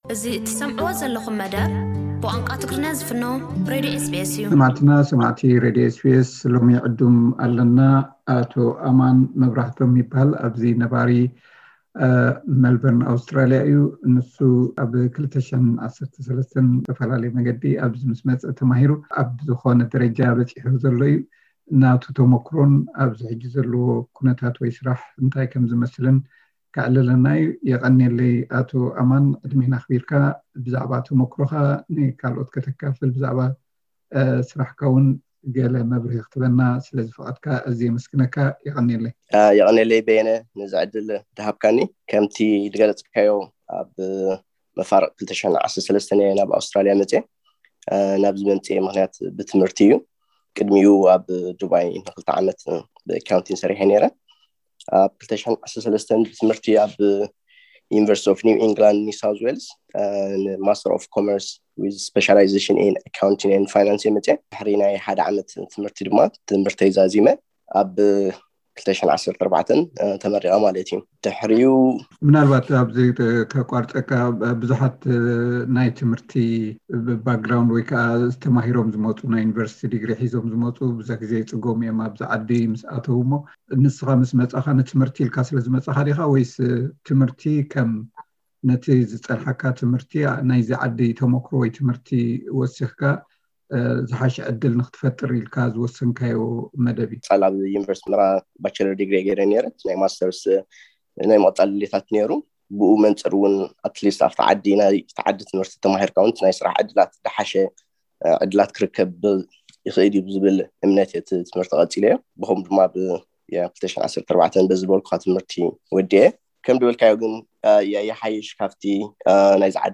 ኣብ ናይ ቃለ መሕትት መደብና ክቐርብዩ።